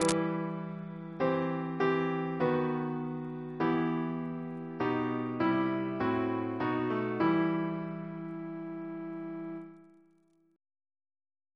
CCP: Chant sampler
Single chant in E minor Composer: Edwin George Monk (1819-1900), Organist of York Minster Reference psalters: ACB: 91; RSCM: 197